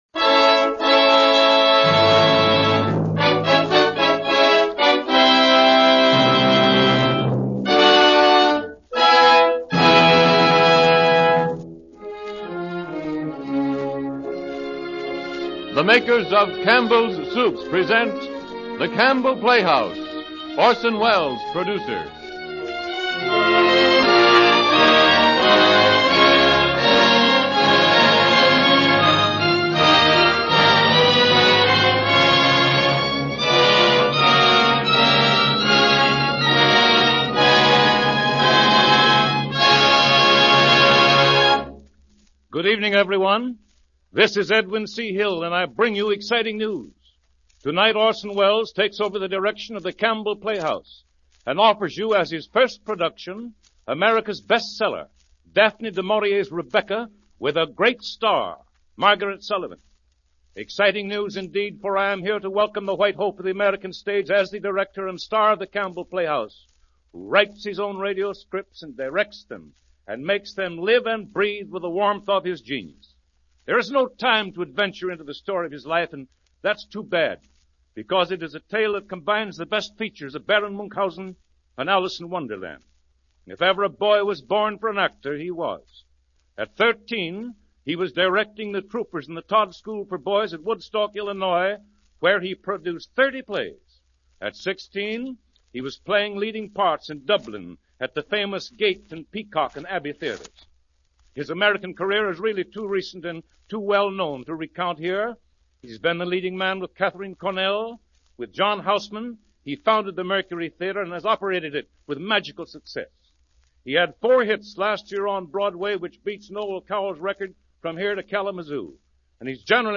The first episode of The Campbell Playhouse, titled “Rebecca,” is a radio drama adaptation of Daphne du Maurier’s novel. Directed by and starring Orson Welles, this series aired on CBS from 1938 to 1941.